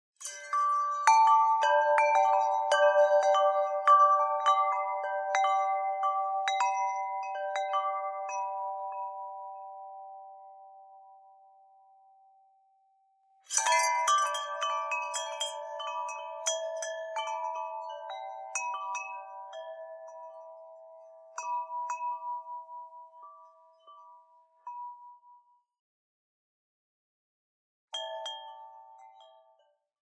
Den blide lyd fra vindklokken bringer et strejf af magi til ethvert rum.
Satellitebox_Wind_Chimes_soundfile_30s.mp3